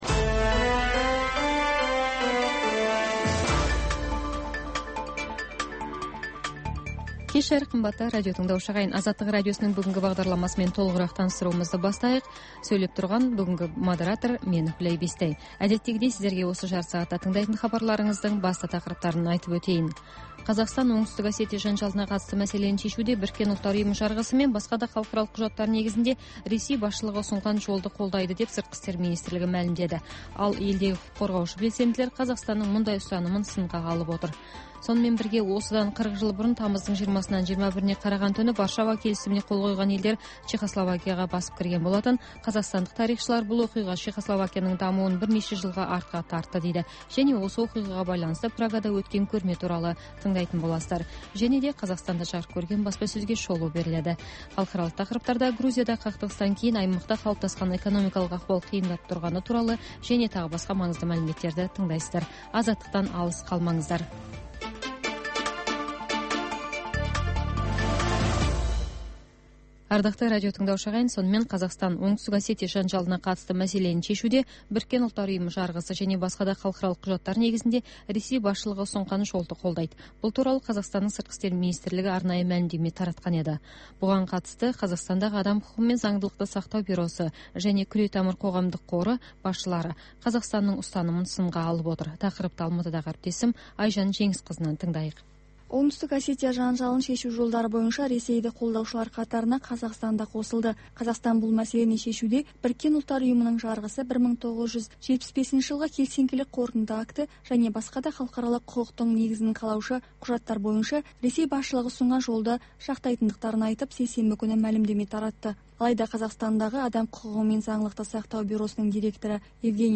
Бүгінгі күннің өзекті тақырыбына талқылаулар, пікірталас, оқиға ортасынан репортаж, сарапшылар талдауы мен қарапайым азаматтардың еркін пікірі, баспасөз шолуы, тыңдарман ойы.